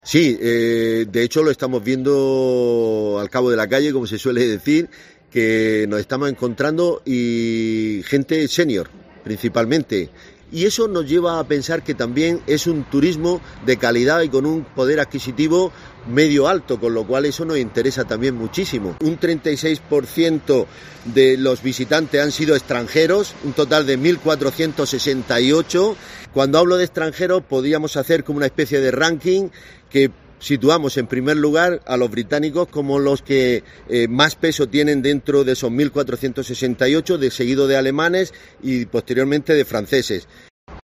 Santiago Parra, concejal Turismo Ayuntamiento de Lorca